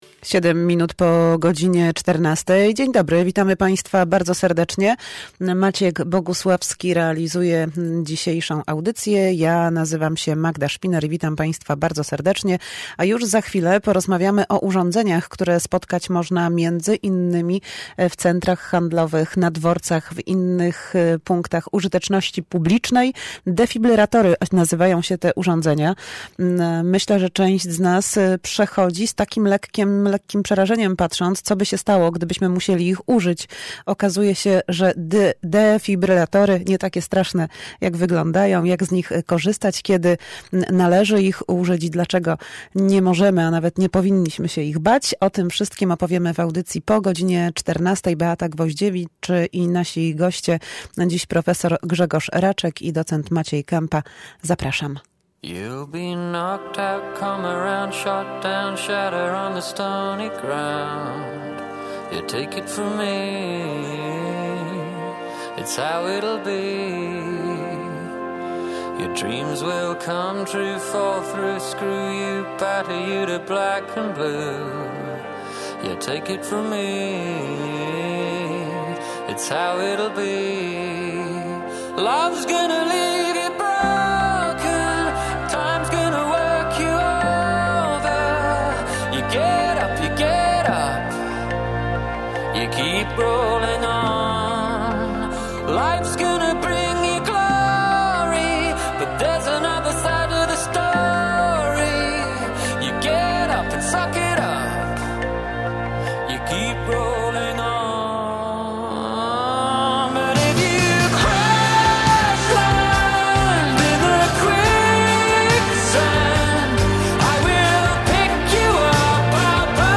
Lekarze odpowiadają